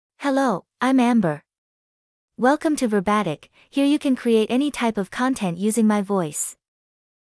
FemaleEnglish (United States)
Amber is a female AI voice for English (United States).
Voice sample
Listen to Amber's female English voice.
Female